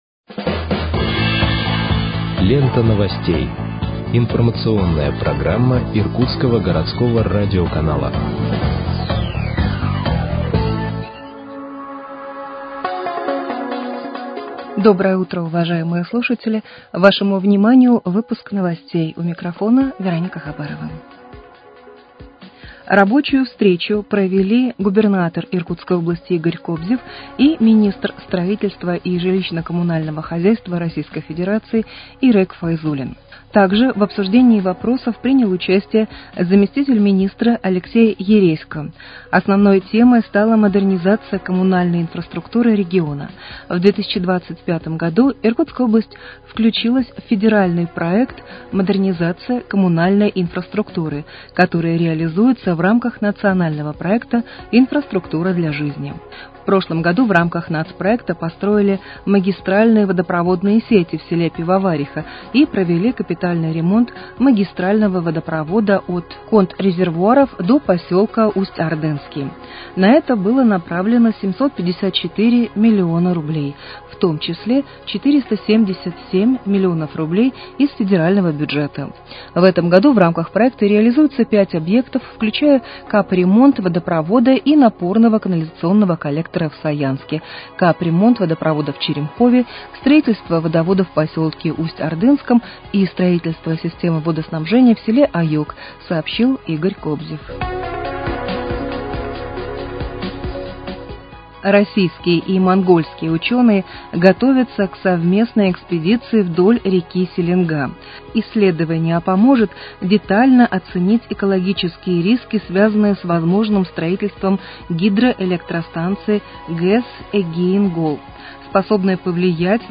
Выпуск новостей в подкастах газеты «Иркутск» от 24.02.2026 № 1